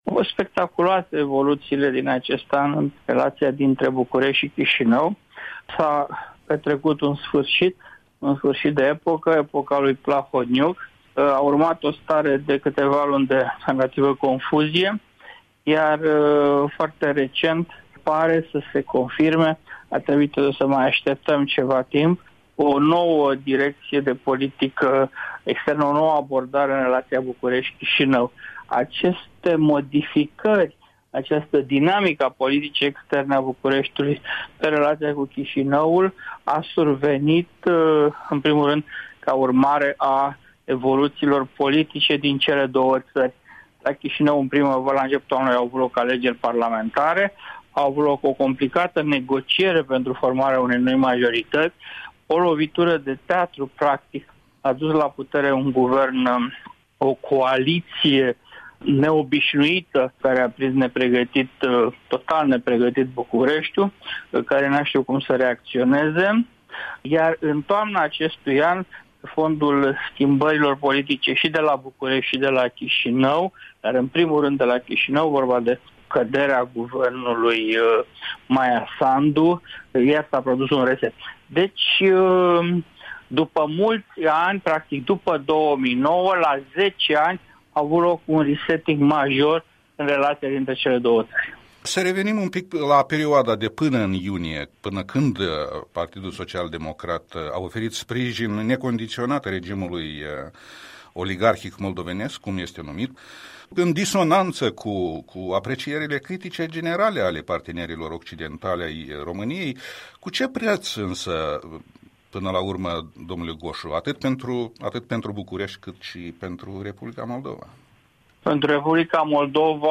Un interviu de bilanț, publicat pe 28 decembrie 2019, cu istoricul și analistul politic de la București despre metamorfozele în relația dintre România și Republica Moldova.